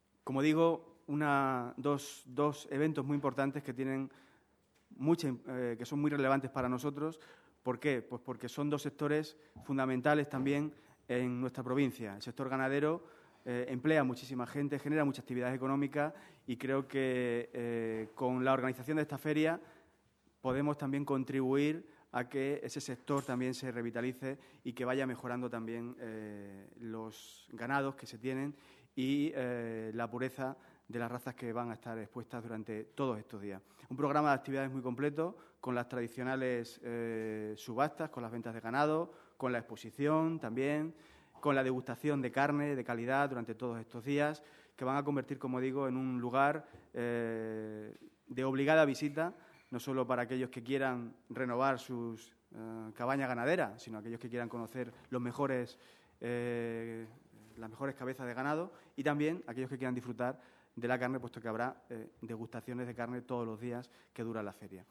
CORTES DE VOZ
12/11/2018.- La XXXV Feria Agroganadera de Trujillo 2018, que se celebrará del 15 al 18 de noviembre en el Mercado Regional de Ganados de la localidad cacereña, cuenta con un presupuesto de 141.000 euros, según ha indicado esta mañana en rueda de prensa Fernando Javier Grande Cano, vicepresidente primero de la Diputación de Cáceres y presidente de la Institución Ferial de Trujillo.